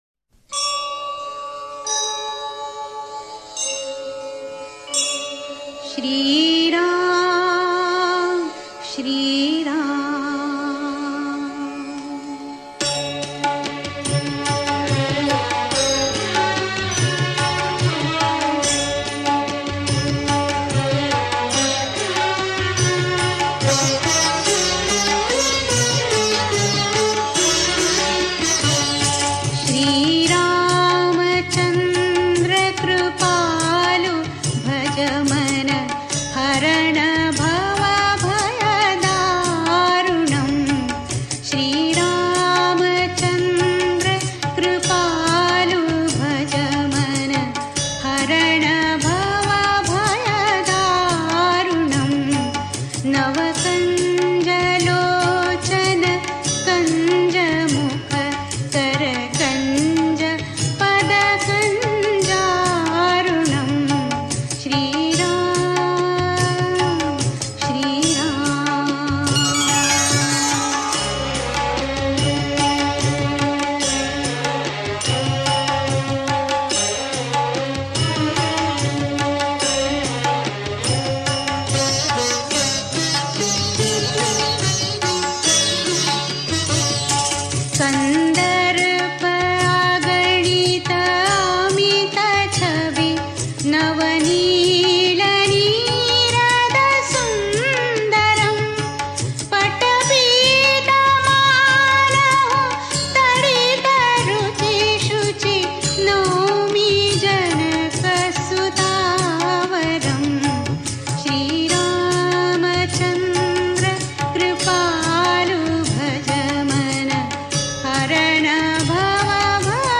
Devotional Songs > Shree Ram Bhajans